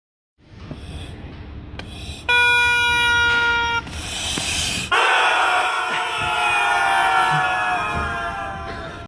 Play Rubber Goose Sound - SoundBoardGuy
rubber-goose-sound.mp3